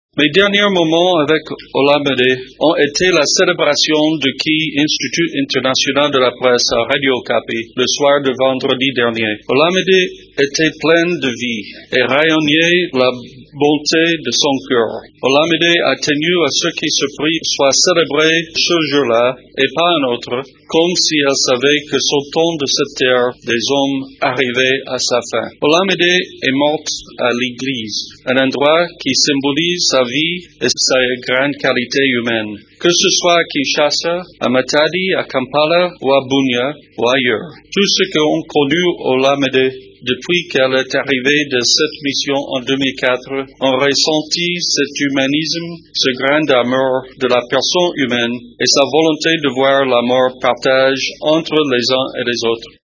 La cérémonie a eu pour cadre le site de Congo Bâtiment de la Mission des Nations Unies pour la stabilisation du Congo, à Kinshasa.
Dans son allocution de circonstance, Roger Meece, représentant spécial du secrétaire général de l’Onu en RDC, a tracé le portrait de la défunte.